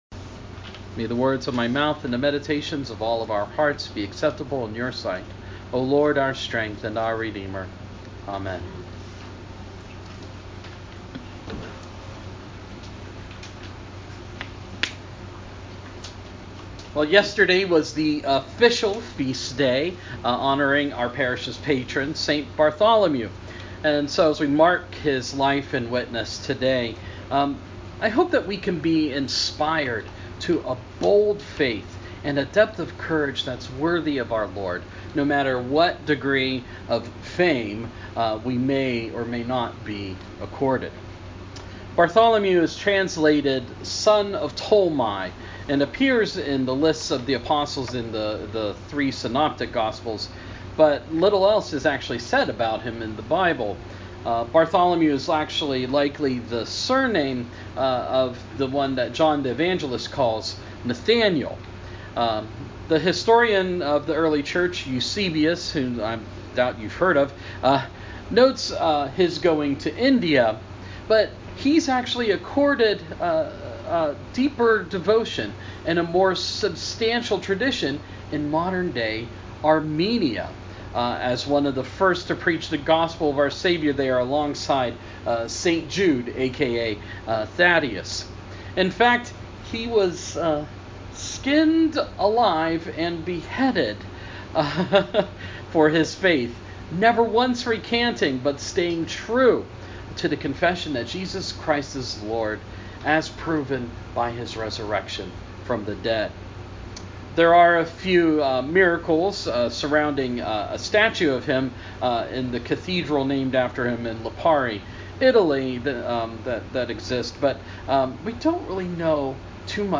Sermon – St Bartholomew’s Feast Observed